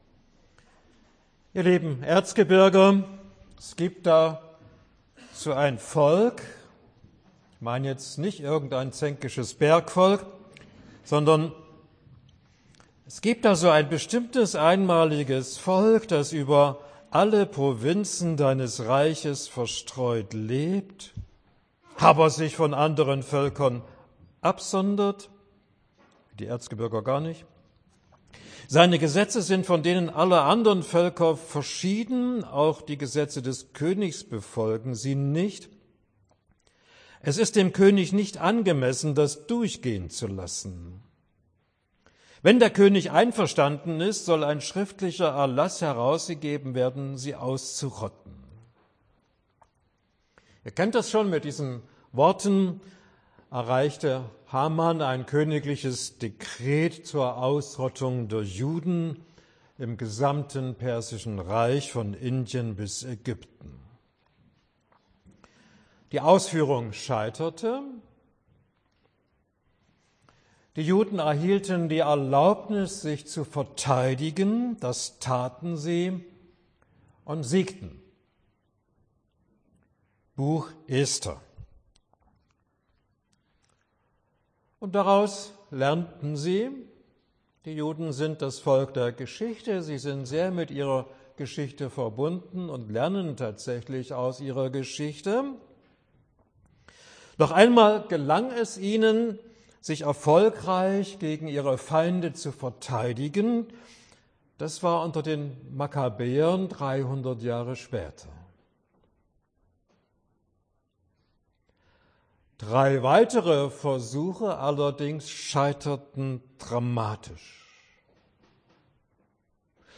Zweiter Vortrag im Zusammenhang mit einer Bibelwoche zum Buch Ester